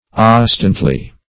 oscitantly - definition of oscitantly - synonyms, pronunciation, spelling from Free Dictionary Search Result for " oscitantly" : The Collaborative International Dictionary of English v.0.48: Oscitantly \Os"ci*tant*ly\, adv.